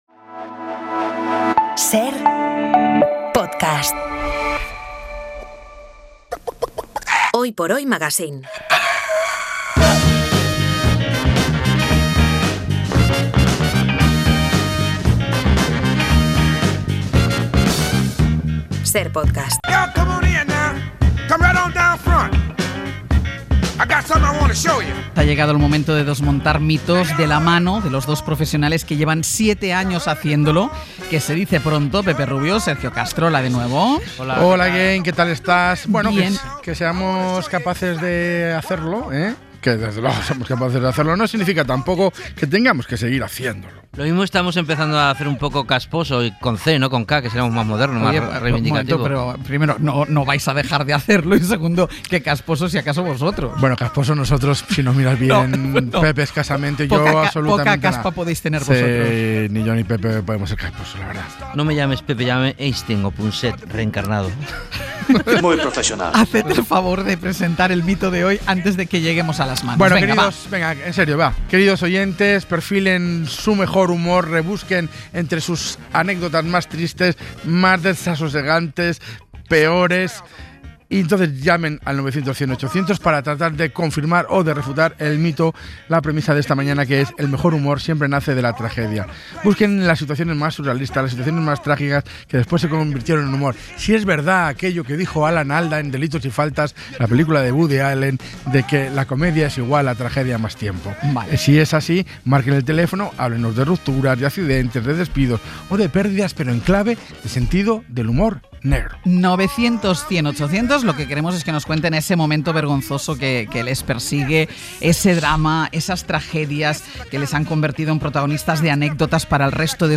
Han contado con llamadas de oyentes